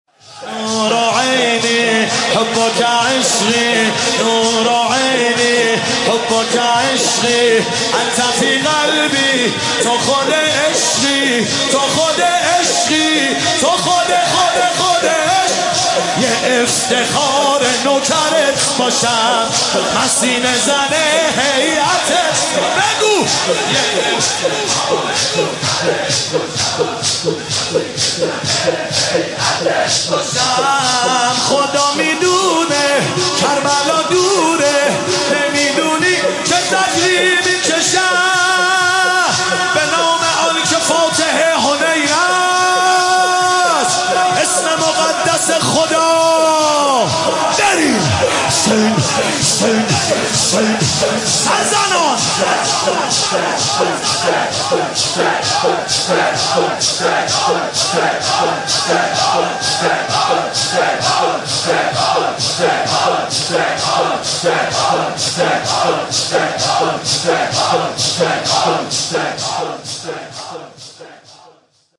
شب پنجم محرم
نوحه جديد
مداحی صوتی